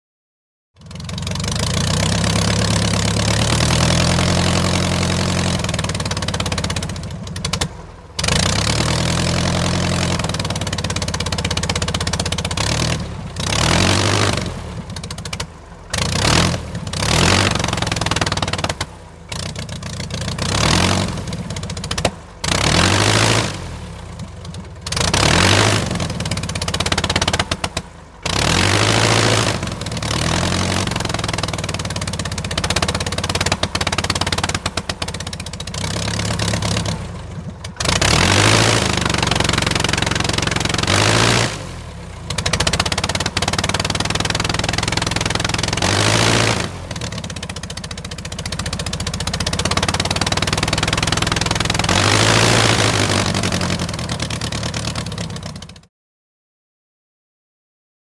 Prop Plane; Idle; Fokker Single Engine Prop Aircraft Circa 1914 Revving With Splutters.